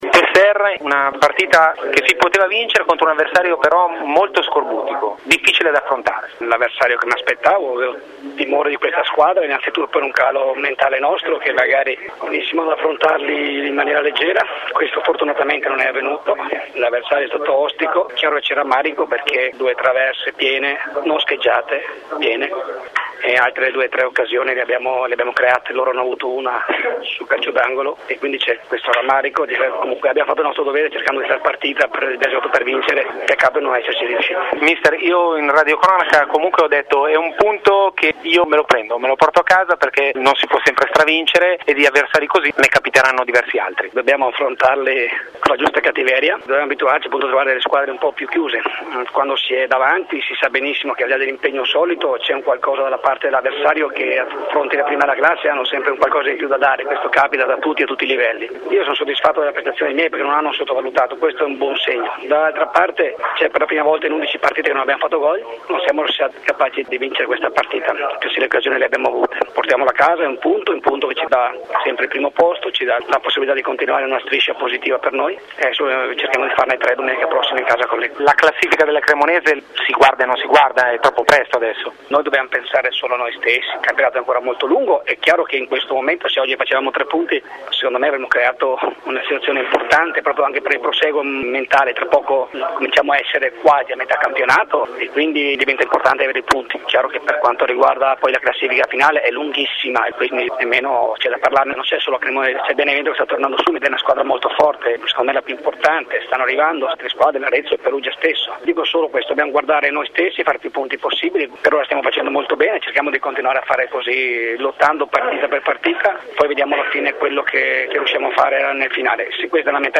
Dichiarazioni dopo gara
servizio offerto grazie alla collaborazione di RADIO AZZURRA FM